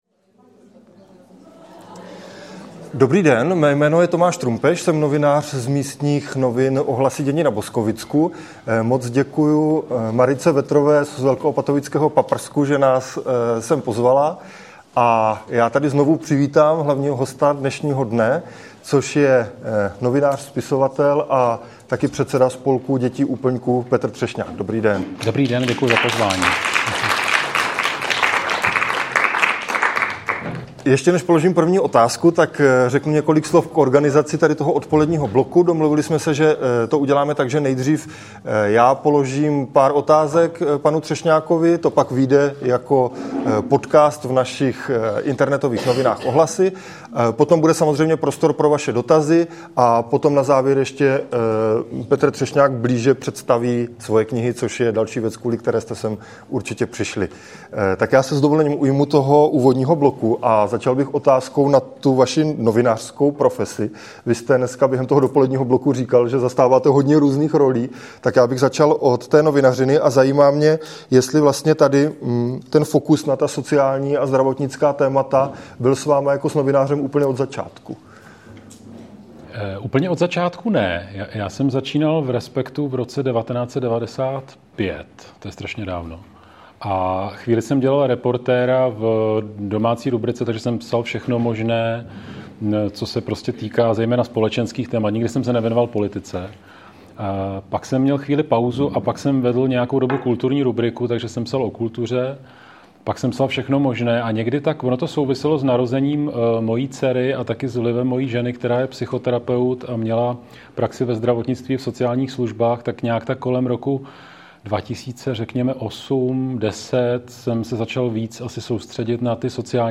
Rozhovor proběhl díky projektu Ohlasy Extra, ve kterém vám společně s Nadačním fondem nezávislé žurnalistiky přinášíme obsah zaměřený na běžně opomíjená témata.